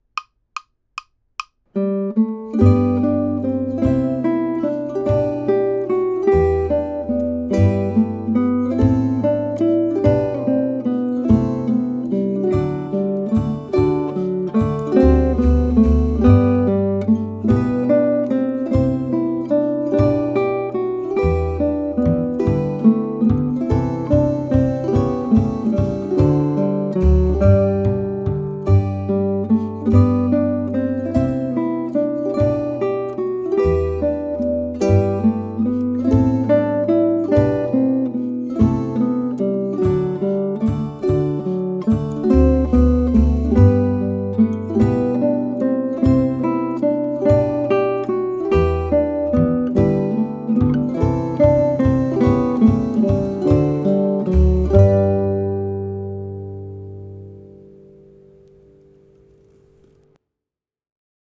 Jesu_Joy_of_Mans_Desiring_(GTR_mix).mp3